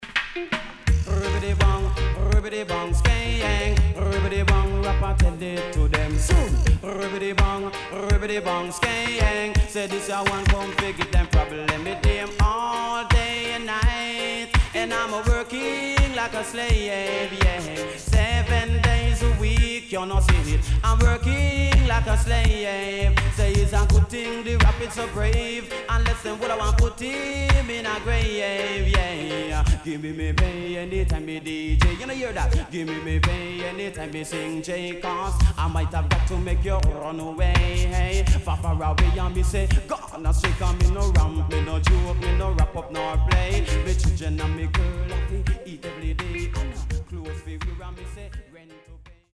HOME > DISCO45 [DANCEHALL]
SIDE A:少しチリノイズ入りますが良好です。